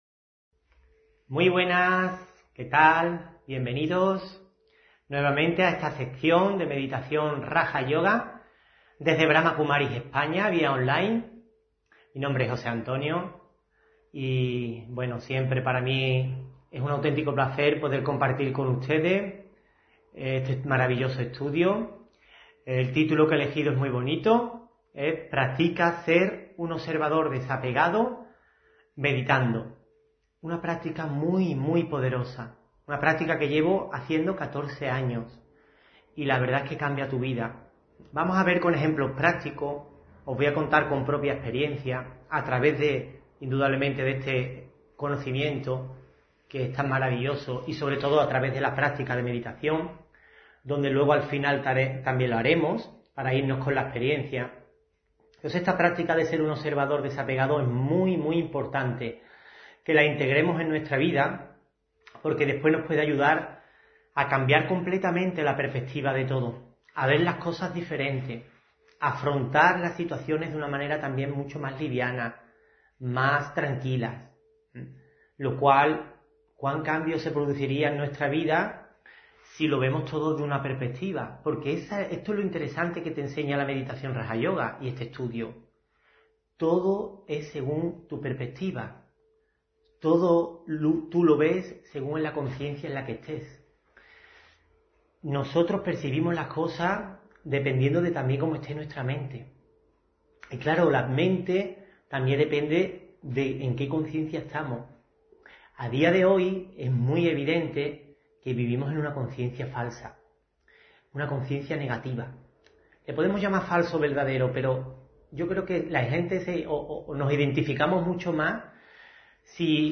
Audio conferencias
Meditación y conferencia: Practica ser un observador desapegado meditando (28 Agosto 2023)